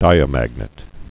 (dīə-măgnĭt)